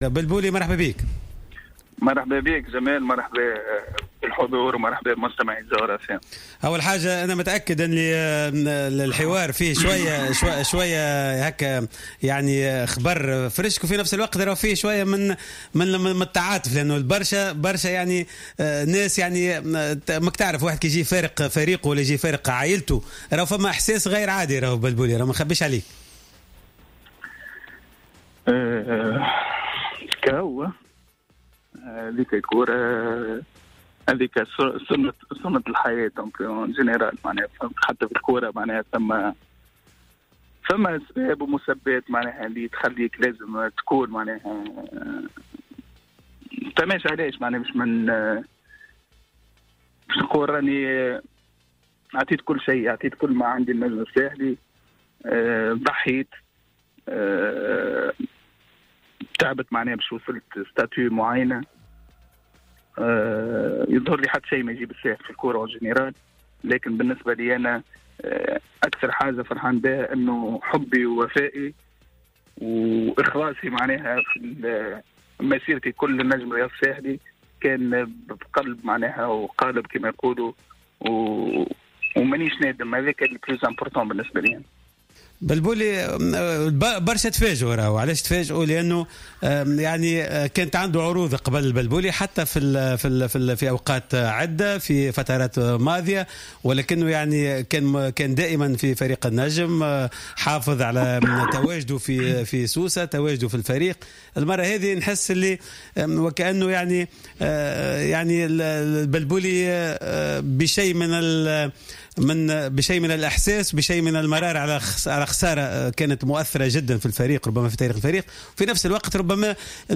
تدخل الحارس أيمن البلبولي في حصة راديو سبور للحديث حول خروجه من النجم الساحلي و الإتفاق مع فريق الباطن السعودي.